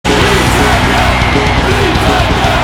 Hier die beiden Torsounds:
Bayern-Tor: